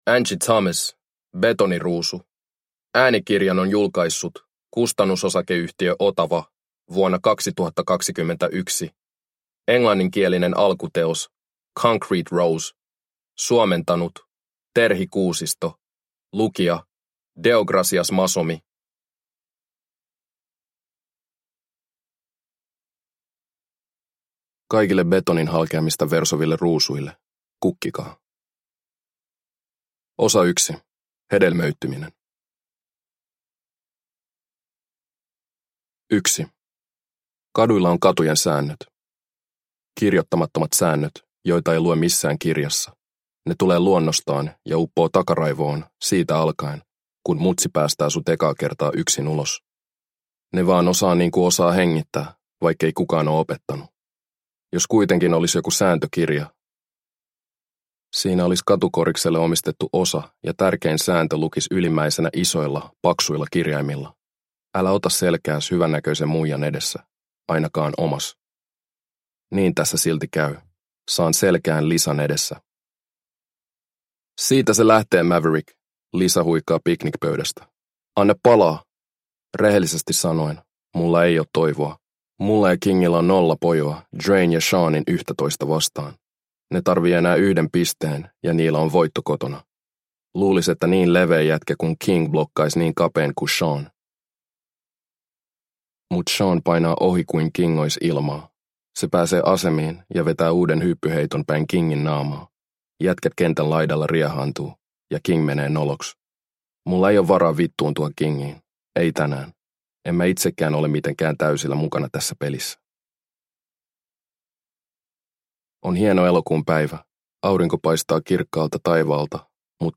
Betoniruusu – Ljudbok – Laddas ner